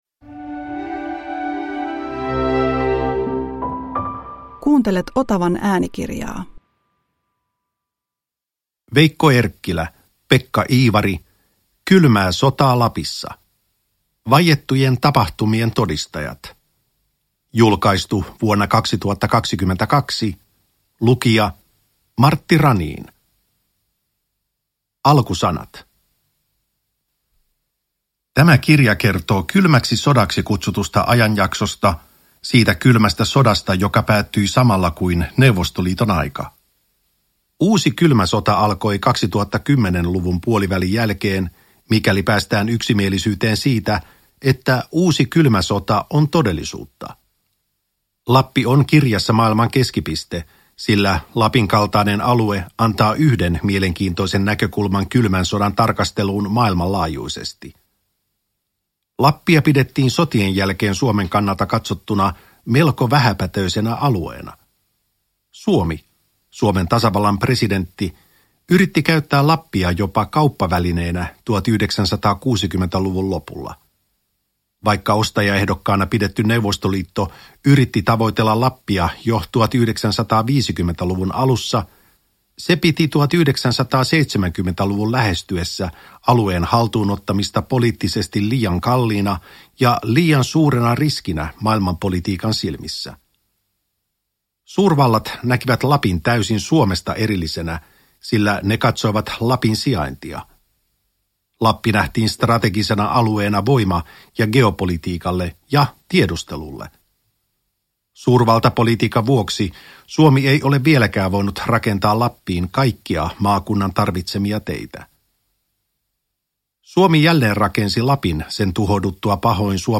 Kylmää sotaa Lapissa – Ljudbok – Laddas ner